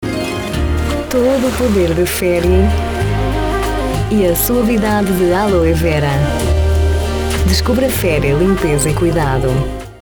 Portuguese flexible female voice with different registers, from a woman, young mother's to a child's. Voice over; Narration; Institutional videos; Documentary; Publicity.
Sprechprobe: Sonstiges (Muttersprache):
Professional Portuguese VO talent.